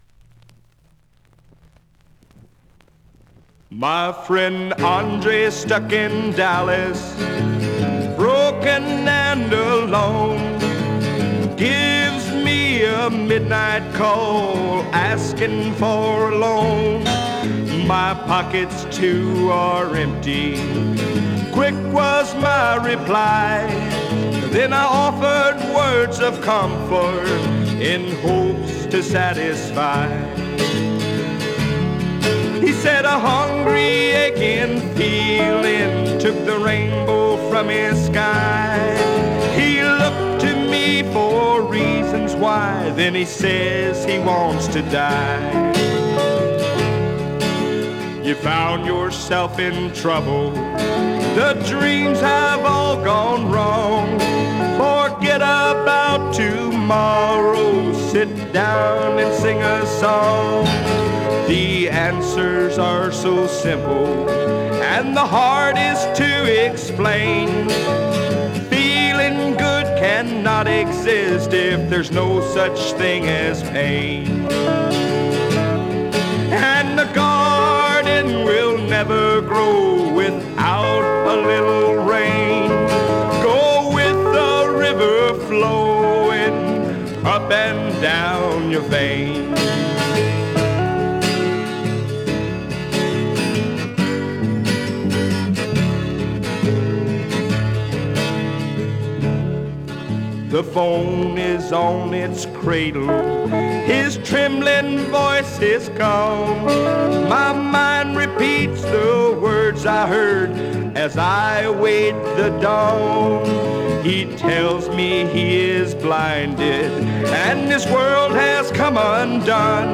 1970 Demo Album